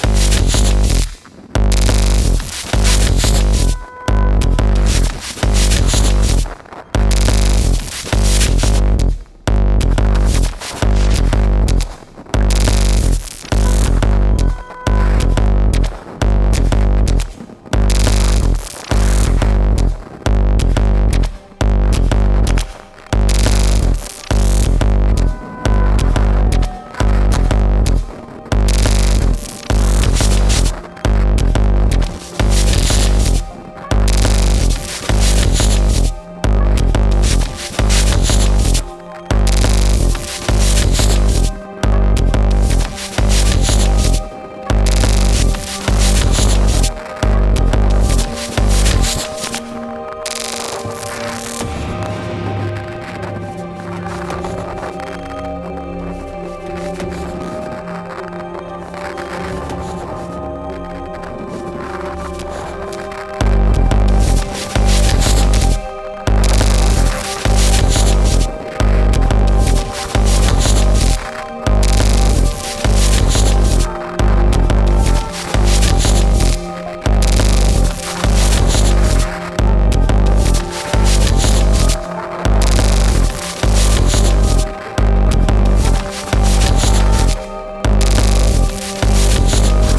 Techno Wave EBM